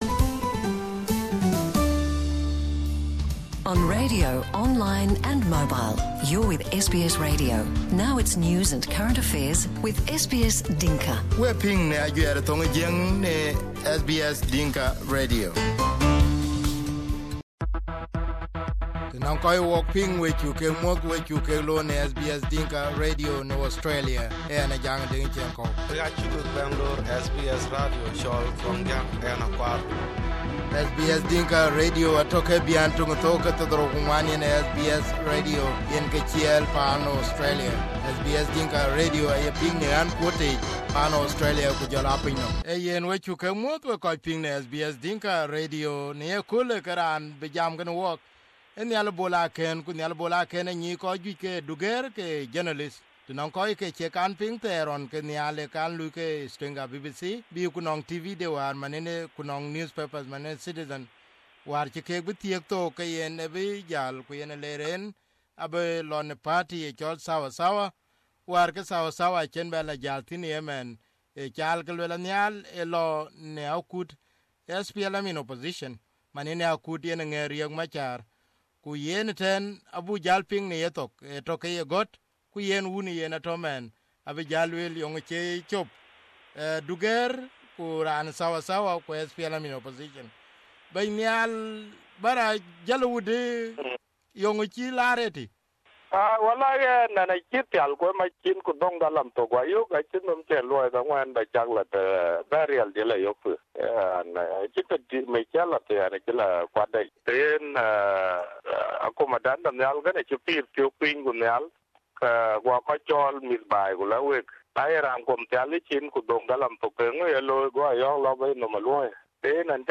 Here is the interview on SBS Dinka